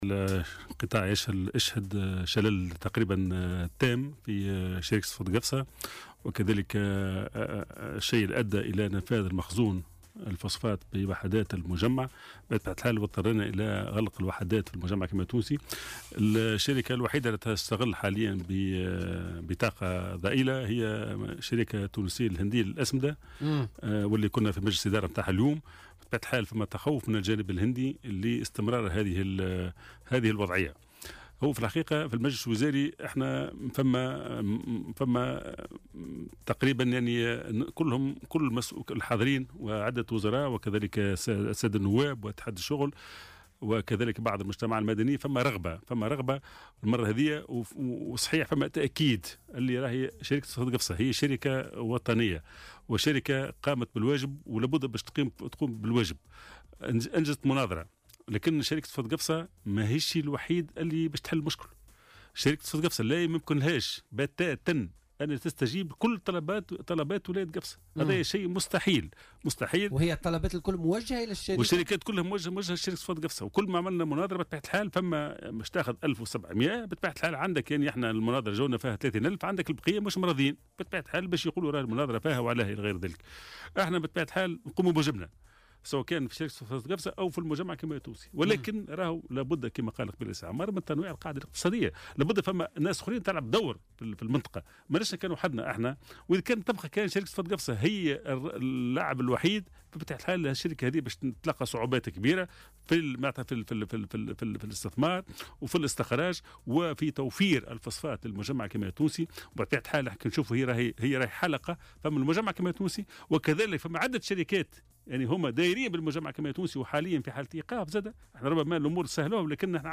وأضاف في مداخلة له اليوم في برنامج "بوليتيكا" أن الشركة قامت بكل ما يمكن لتوفير مواطن شغل، لكن ليس بإمكانها لوحدها حل مشكل التشغيل والاستجابة لكل الطلبات. وأوضح أن تواصل تعطّل الإنتاج سيكون له انعكاسات سلبية، قائلا إن الخسارة الكبرى هي فقدان الحرفاء الذين سيحثون عن شركات أخرى للتعامل معها.